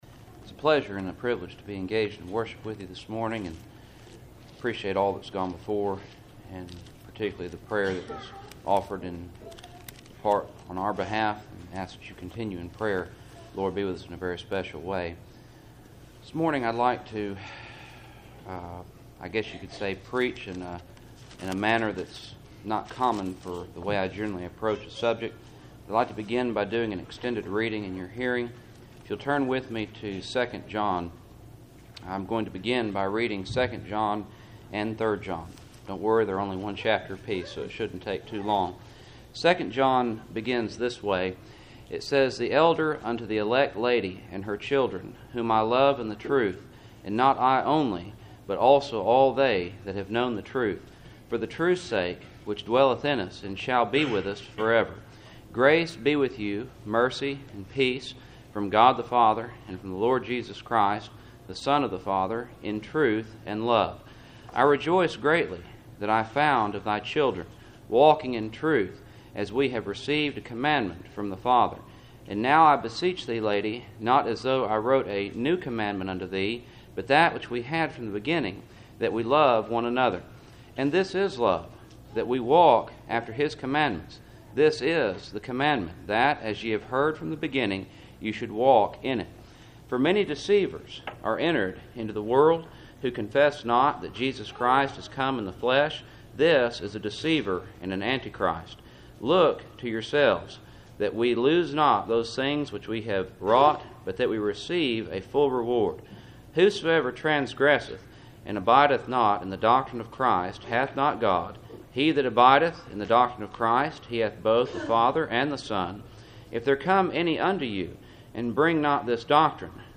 Service Type: Cool Springs PBC Sunday Morning